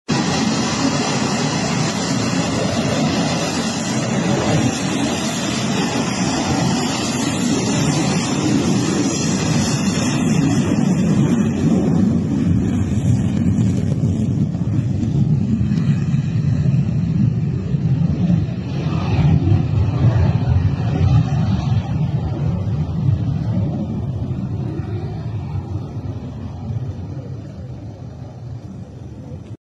A US F/A 18 Hornet fighter sound effects free download